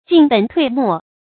進本退末 注音： ㄐㄧㄣˋ ㄅㄣˇ ㄊㄨㄟˋ ㄇㄛˋ 讀音讀法： 意思解釋： 本：根本；末：枝節。